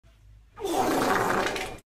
Wet Fart Noise Efecto de Sonido Descargar
Wet Fart Noise Botón de Sonido